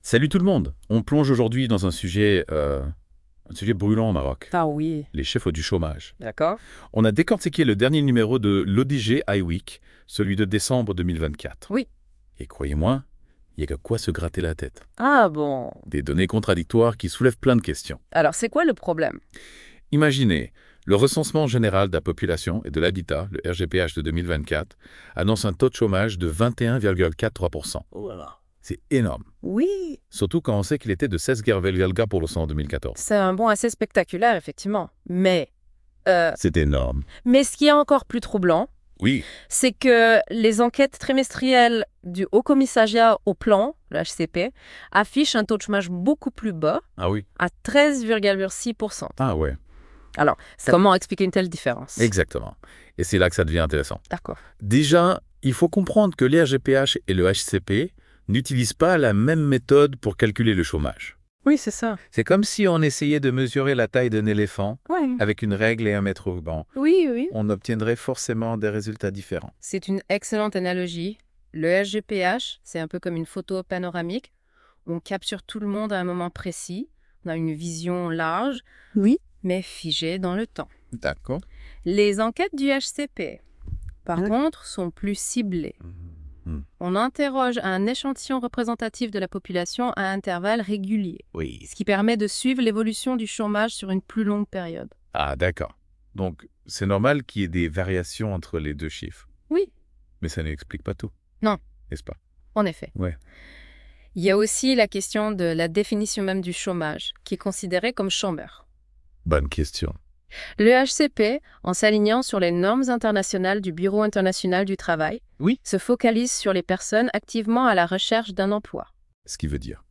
+ Débat - Podcast : les chroniqueurs de la Web Radio débattent des idées contenues dans cet Hebdomadaire à travers ces questions